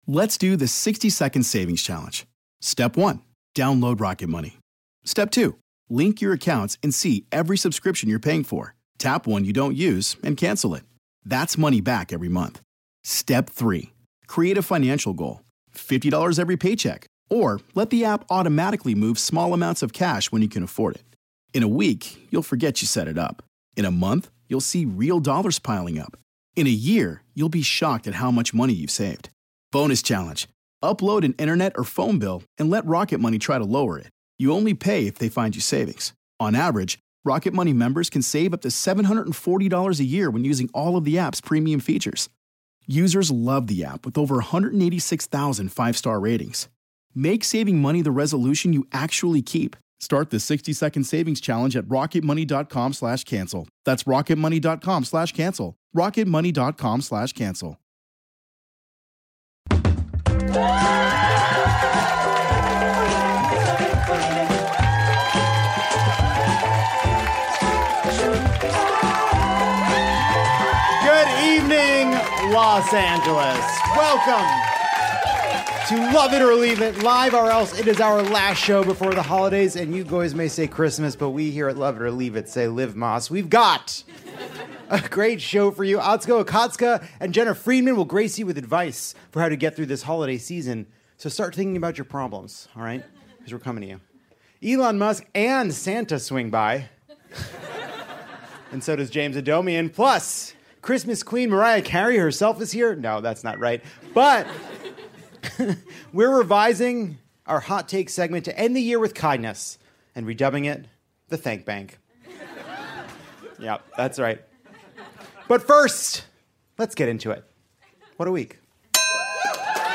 Mike Lindell (James Adomian) stops by with pillows for all the good little boys and girls of the RNC. Jena Friedman and Atsuko Okatsuka pull up a chair to your family’s holiday drama. Elon Musk (James Adomian) tries his hand at stand-up and spoiler alert: the audience is wrong. Lovett provides a personalized gift guy for the right-wing whack jobs in your life, and we end by putting our grateful well-wishes in the Thank Bank.